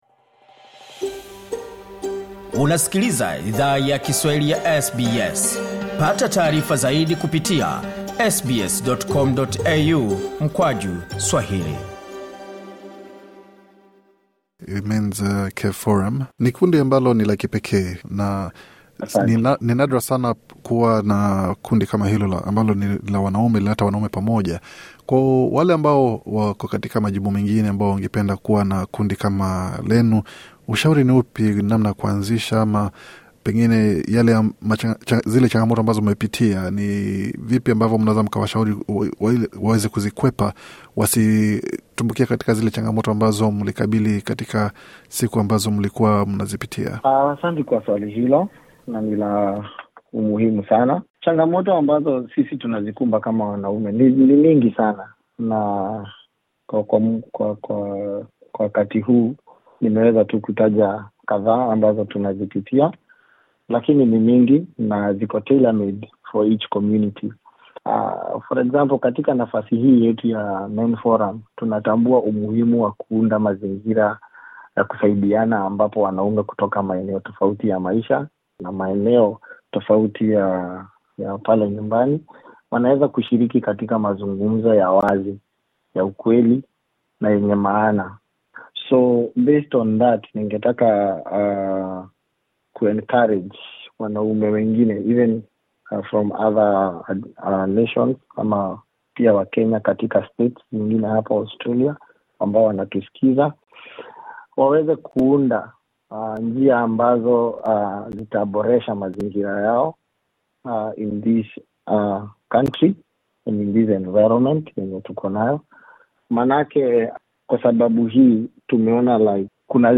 Katika mahojiano maalum